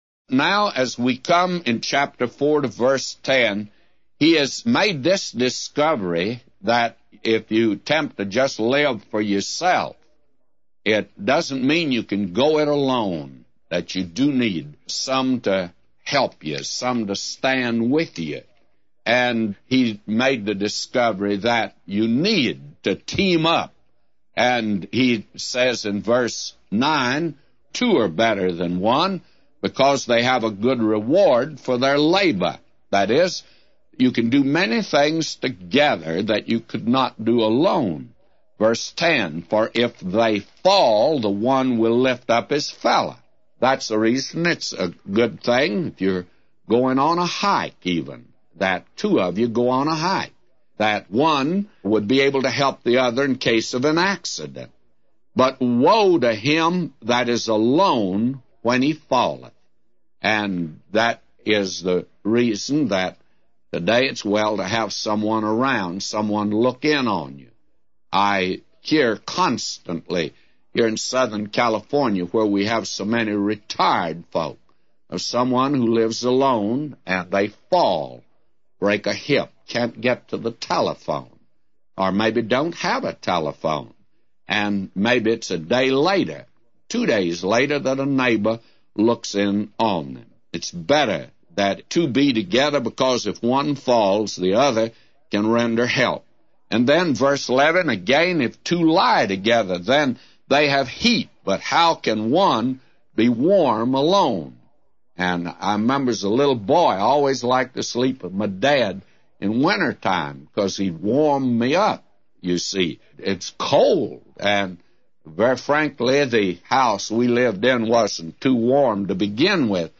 A Commentary By J Vernon MCgee For Ecclesiastes 4:10-999